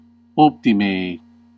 Ääntäminen
US : IPA : [bɛst]